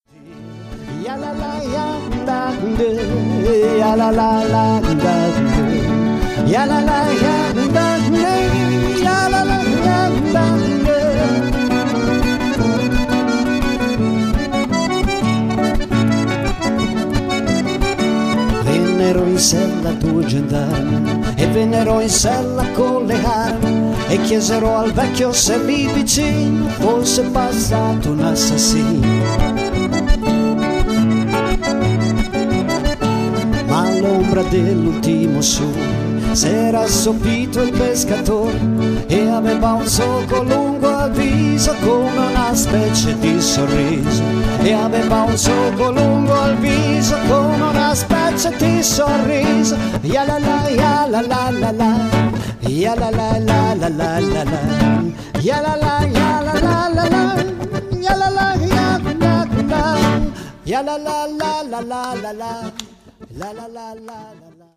Chant - Guitare - Mandoline - Accordéon - Voix recitant
QUELQUES EXTRAITS DE MUSIQUE LIVE DU RECITAL